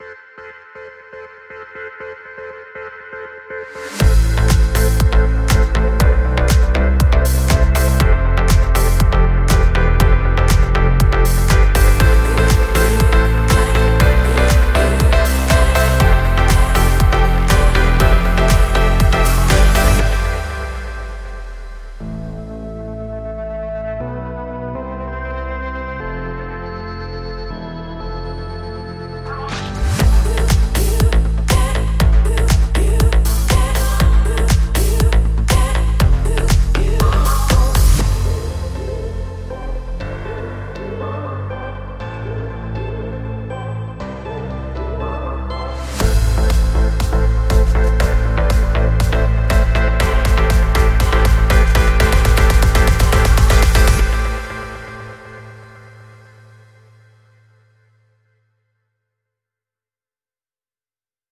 键盘钢琴 Sample Logic Key Fury KONTAKT-音频fun
他不止是一架钢琴，还是具有多种特殊效果的创意音源。
- 它使用了多种键盘乐器的采样，包括大钢琴、电钢琴、合成器、有机键盘等，制作出了富有戏剧性和情感的电影风格的音色。
- 它具有热插拔效果链技术，可以随时更换和调整音色的效果，包括滤波、失真、混响、延迟等。